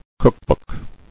[cook·book]